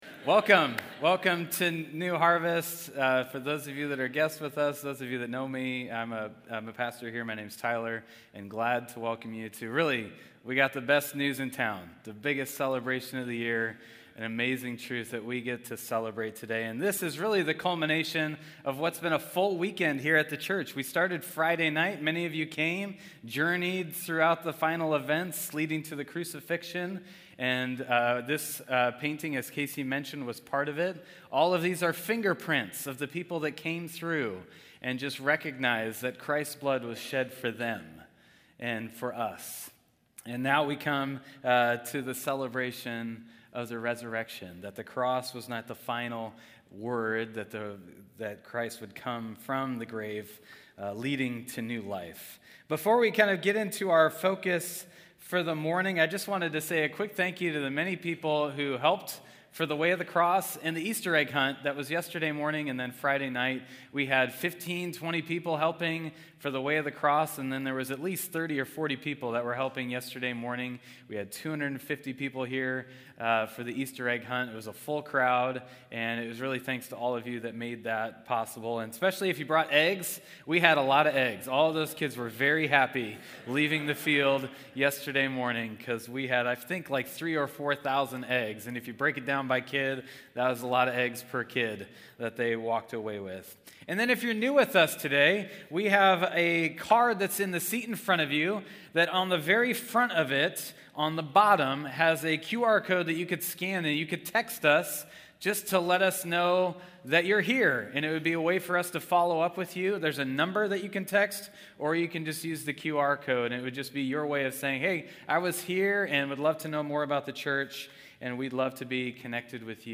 Podcast Audio | Sermons Oh Death, Where is Your Sting?